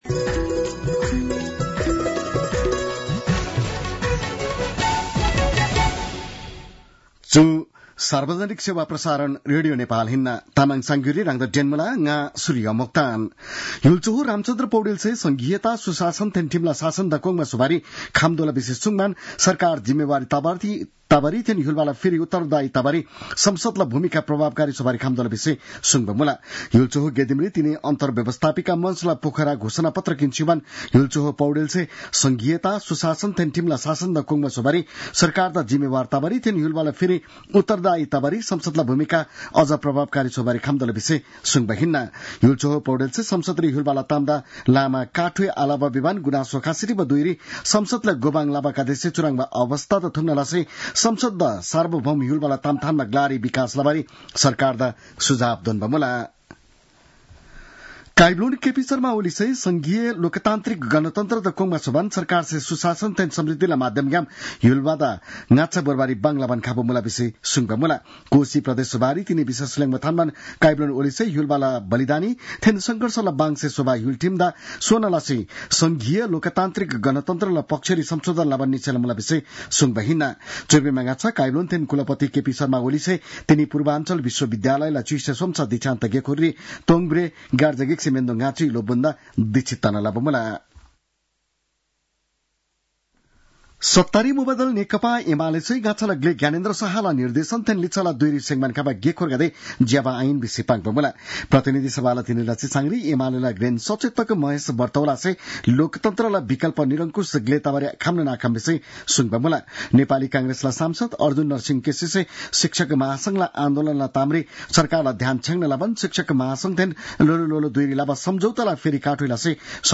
तामाङ भाषाको समाचार : २८ फागुन , २०८१